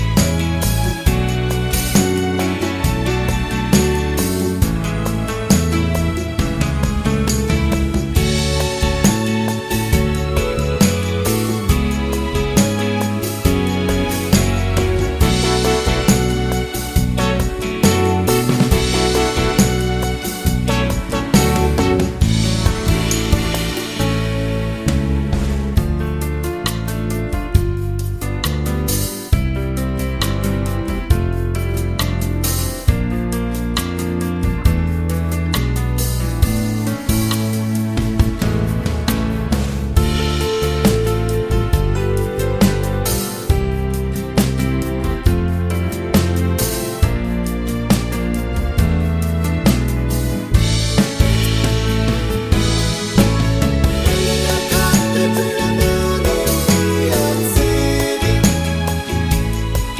no sax solos Pop (1980s) 3:47 Buy £1.50